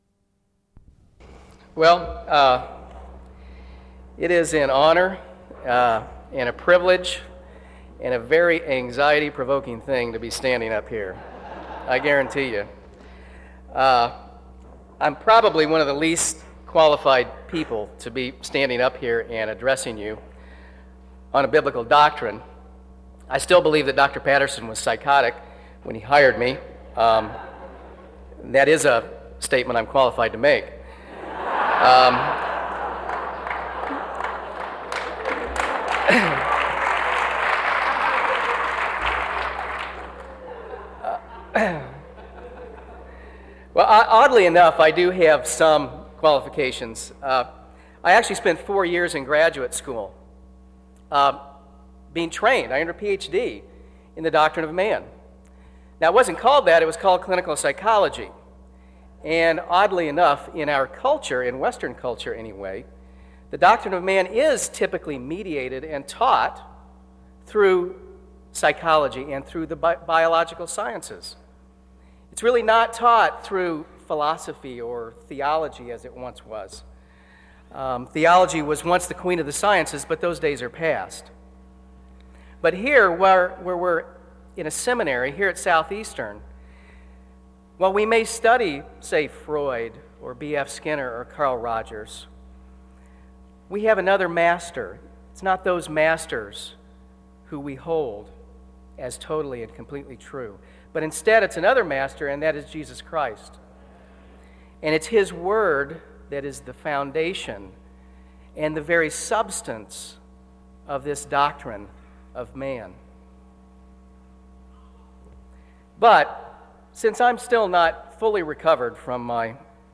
In Collection: SEBTS Chapel and Special Event Recordings - 2000s